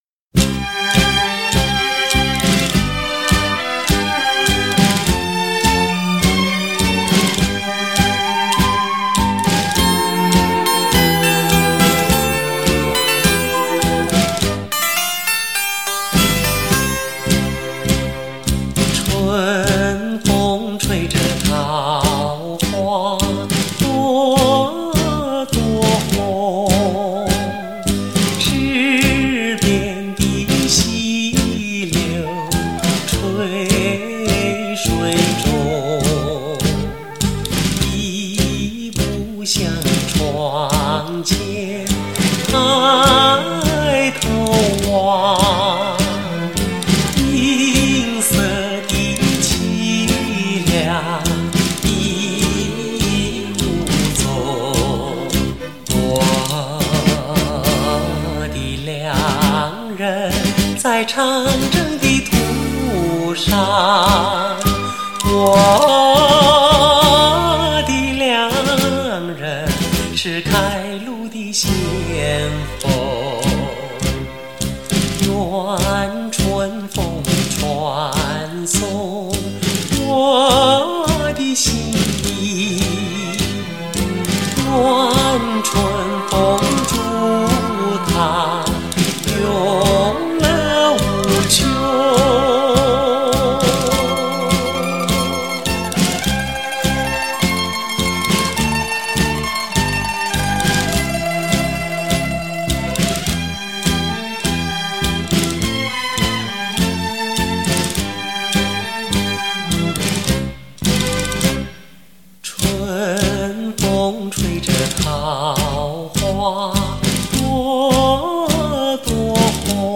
为了获得最好的音响效果，原来的母带也经过高清晰数码加工处理。